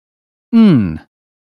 uk_phonetics_sound_sudden_2023feb.mp3